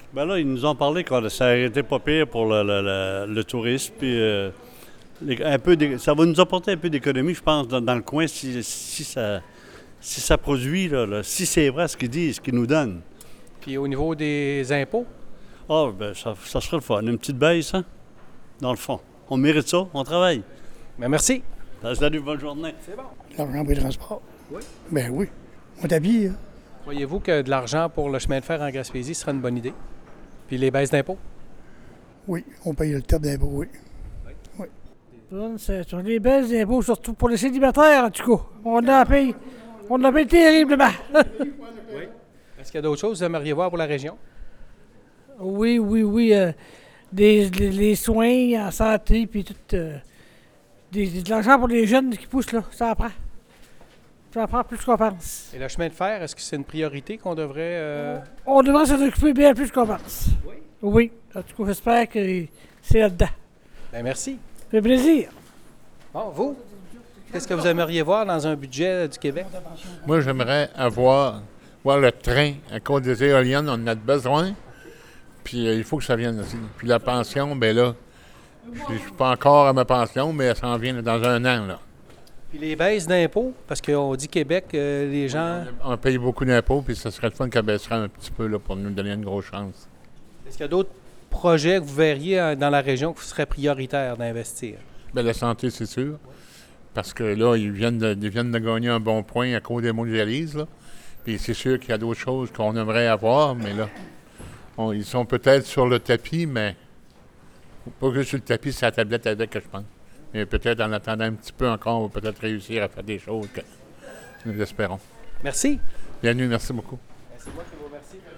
Enfin, des commentaires de la population sur les attentes face au budget: